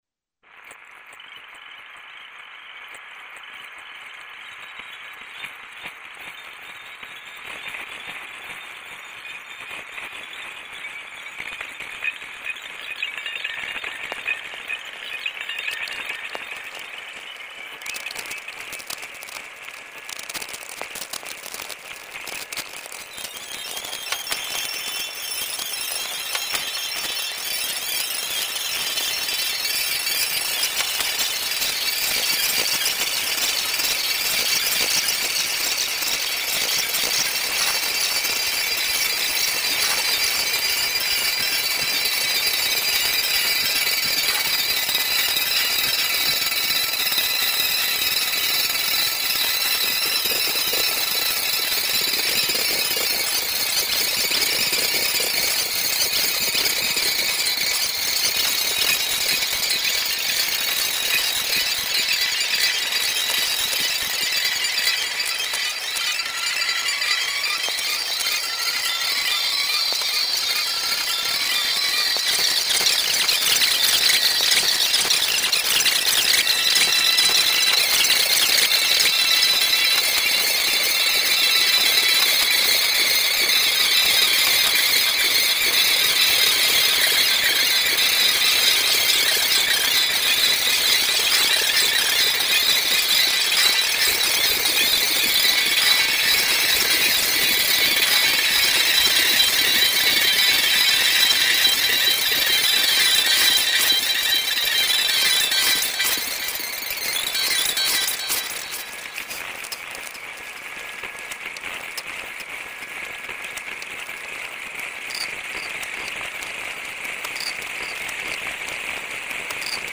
obviously dark, sometimes even dark ambient-like